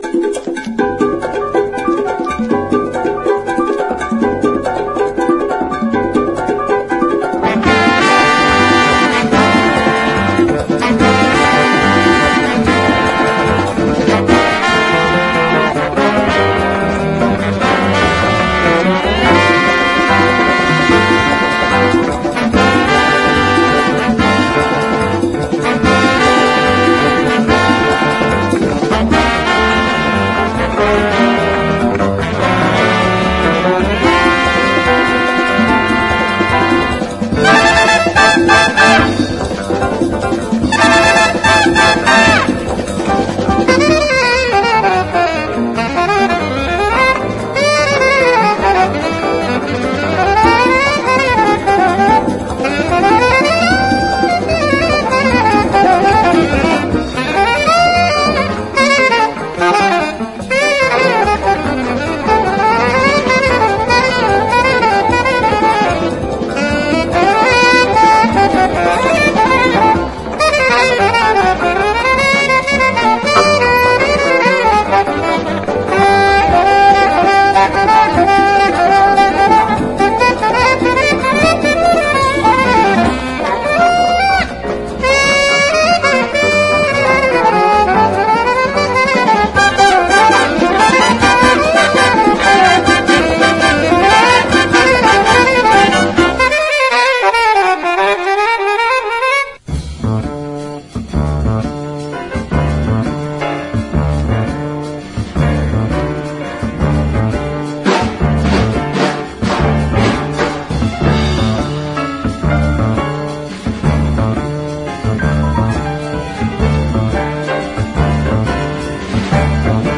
レアなジャズ・ファンク/レアグルーヴ盛り沢山の超即戦力コンピレーション！